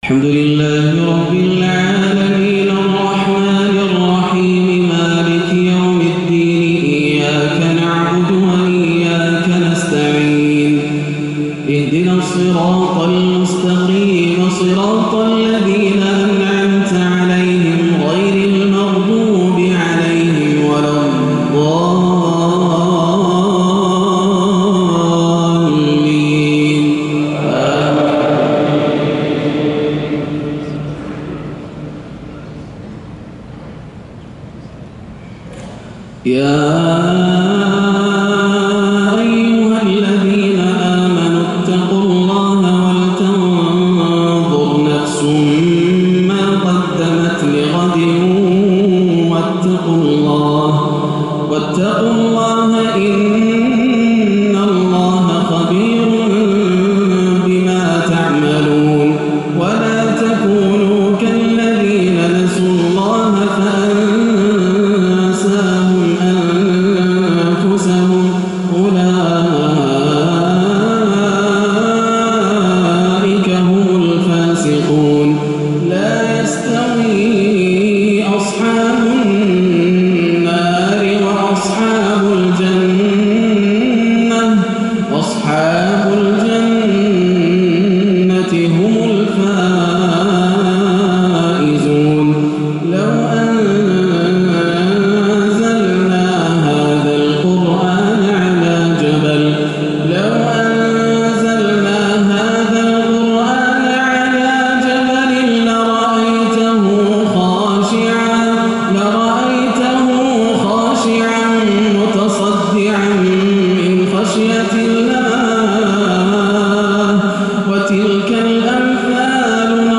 صلاة الجمعة 8-7-1437هـ خواتيم سورة الحشر 18-24 و سورة النصر > عام 1437 > الفروض - تلاوات ياسر الدوسري